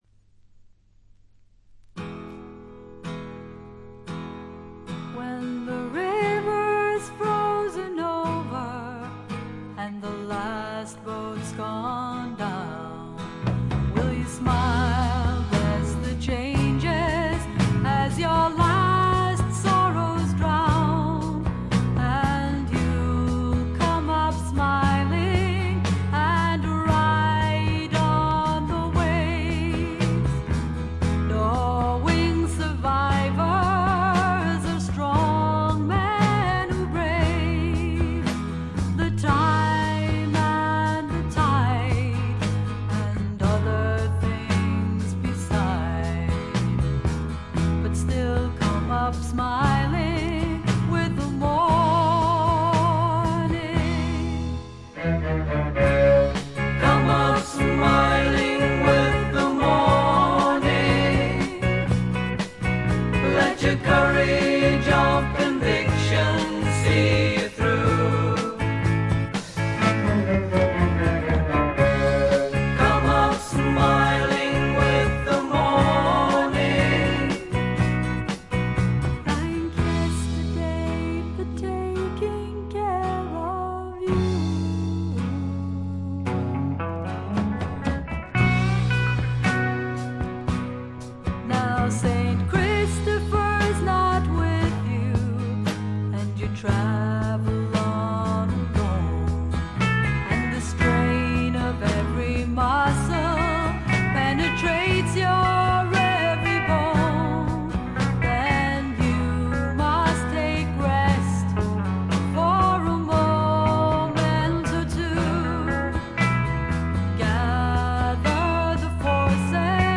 他は軽微なチリプチが少々。
試聴曲は現品からの取り込み音源です。